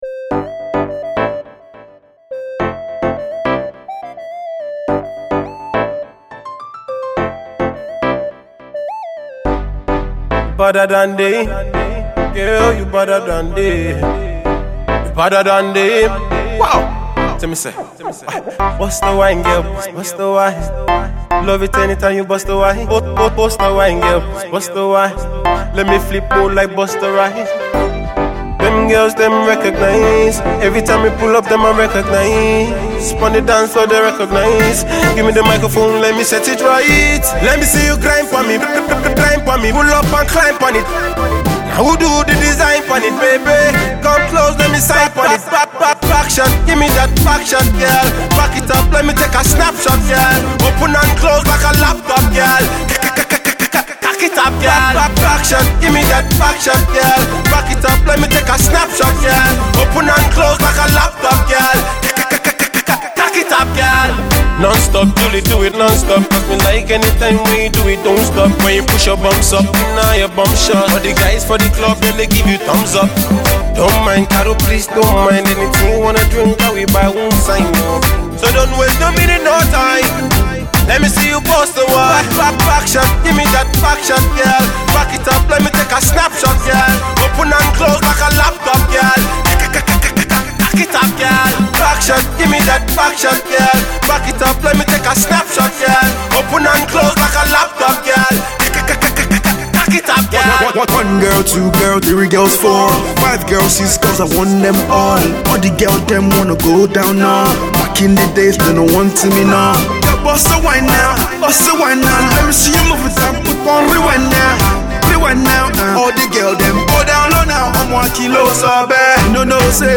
Are ready for the Dancehall invasion?